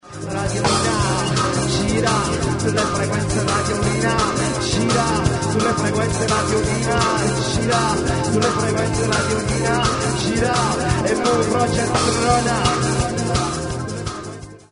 jingle
registrato al concerto